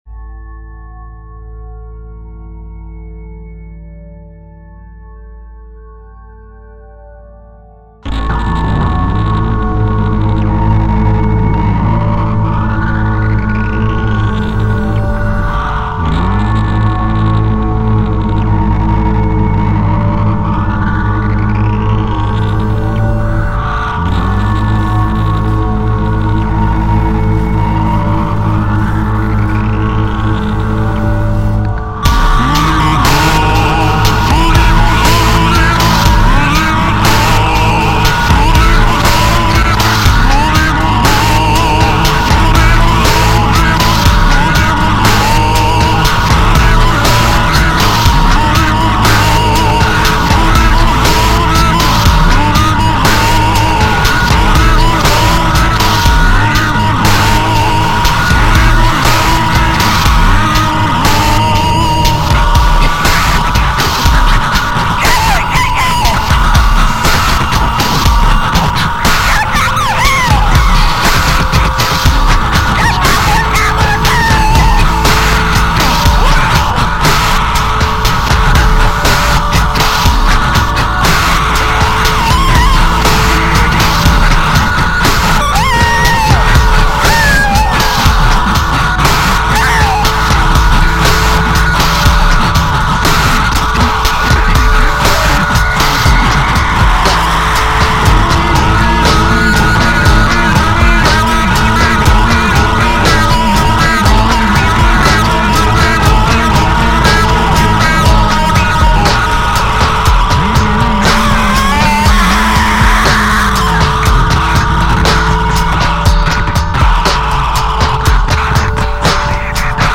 much more minimalistic and experimental